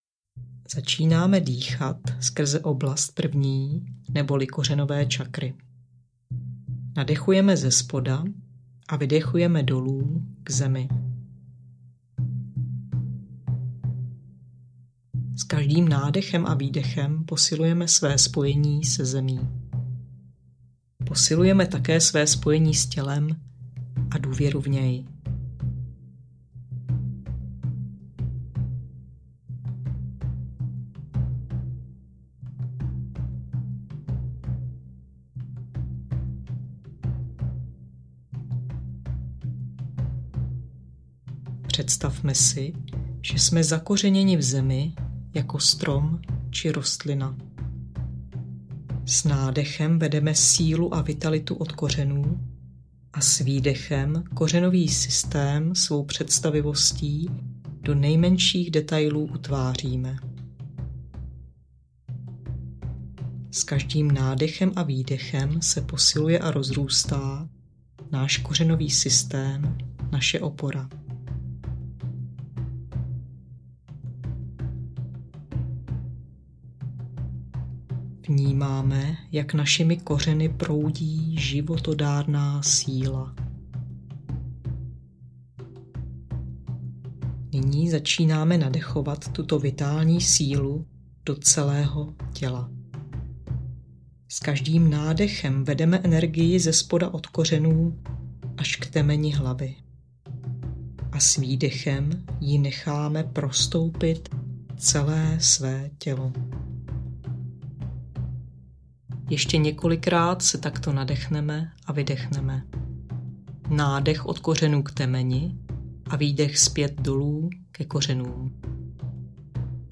Sebeléčba audiokniha
Ukázka z knihy